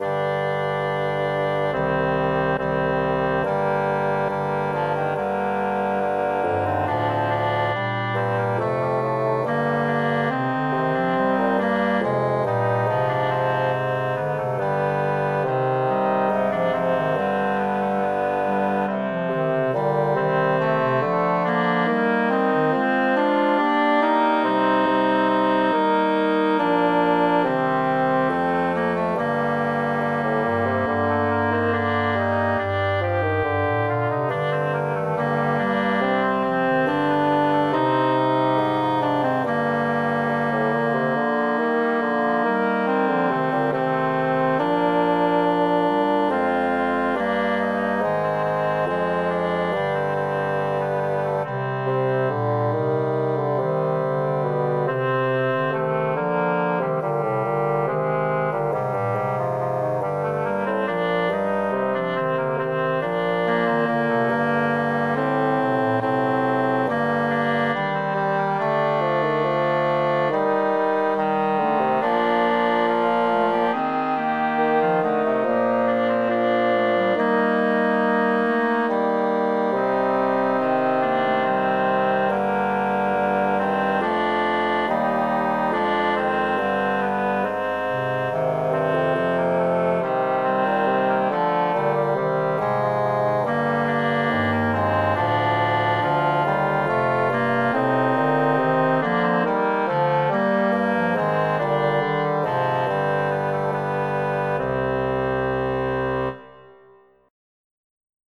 Brumel,_DuToutPlongiet_(MIDI).ogg.mp3